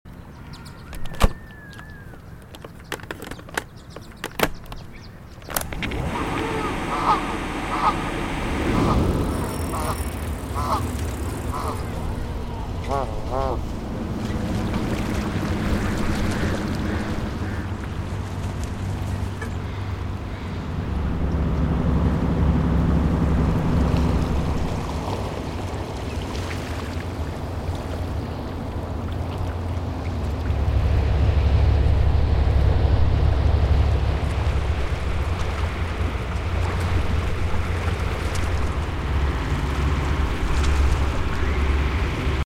In this episode, we begin in David Lam Park, Vancouver. A space where water speaks softly against the harbor’s edge, where wheels hum over the pavement, where geese whisper in the shadows of the city’s towers.